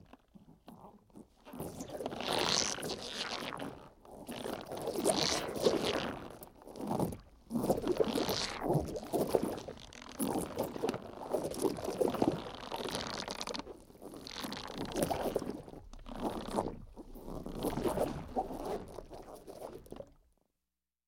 Rubber Stretches; Rubber Processed Stretching And Bending. - Cartoon, Stretchy Rubber